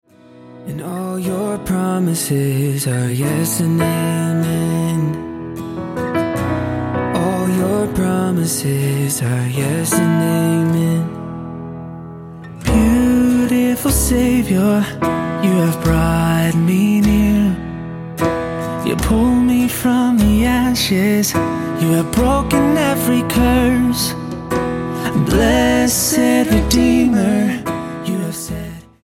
STYLE: Pop
piano